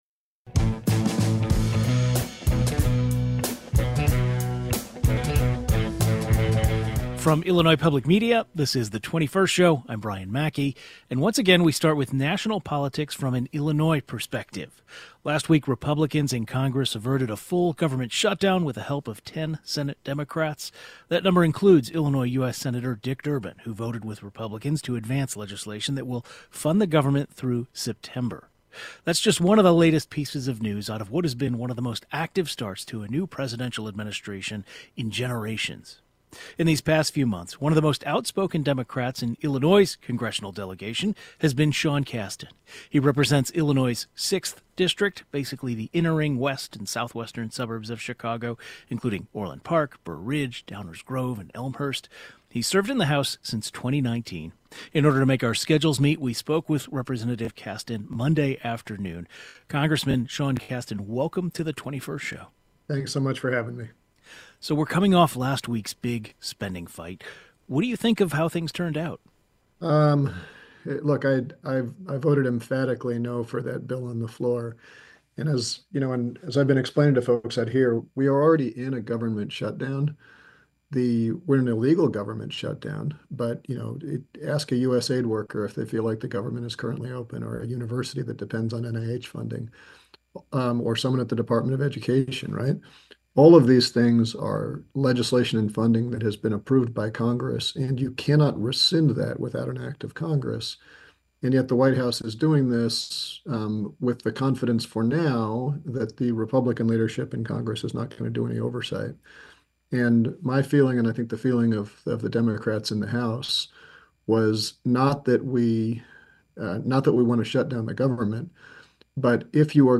Guest: Rep. Sean Casten (D IL-6)